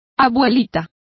Complete with pronunciation of the translation of nanny.